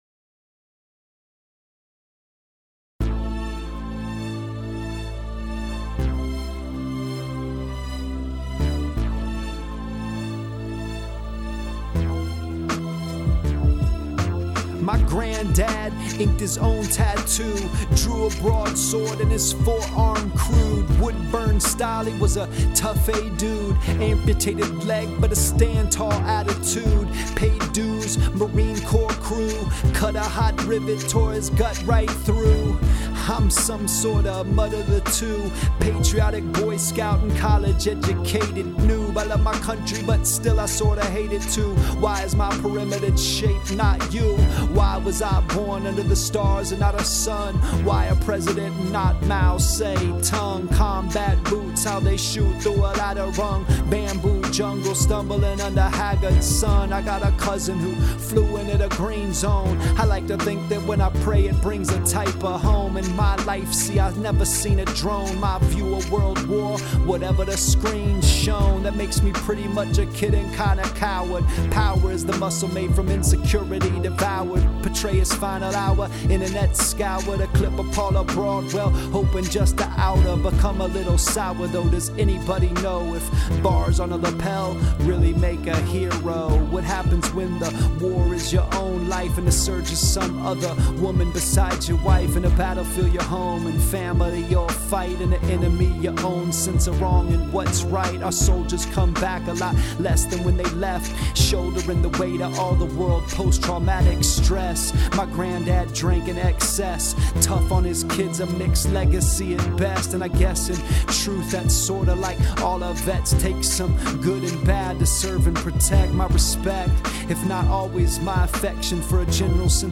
So all the while, I’ve just been playing baseball and going to school and buying video games and starting a family and writing news raps and generally just loafing around.